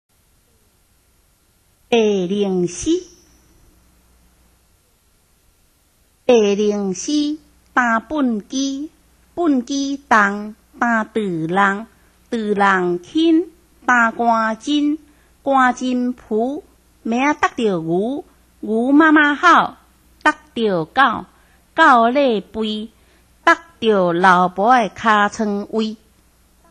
白翎鷥(一) 聽聽看~台語發音